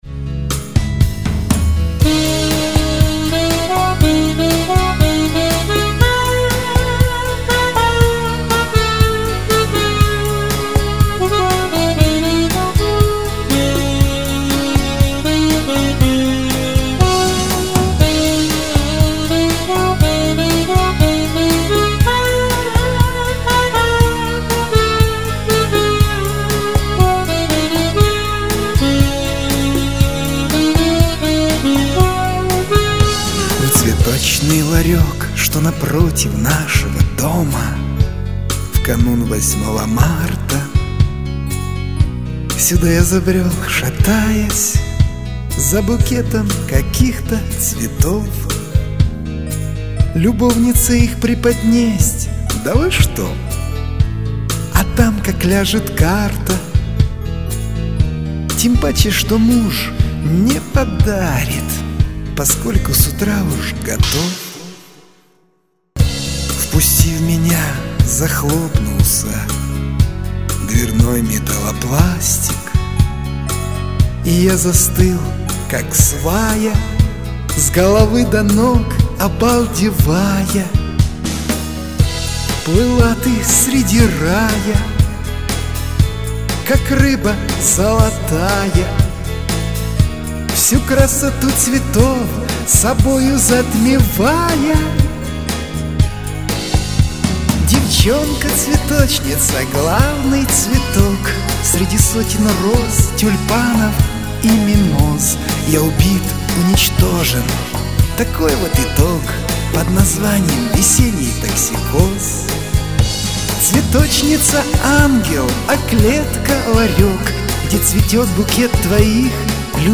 Еще один вариант "цветочницы", где можно разобрать слова.
Рубрика: Поезія, Авторська пісня